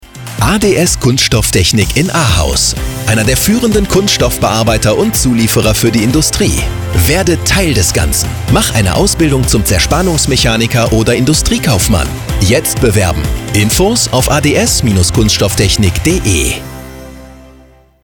Seit heute laufen auf Radio WMW regelmäßig unsere Spots, in denen Ihr über unsere aktuell freien Stellen erfahrt.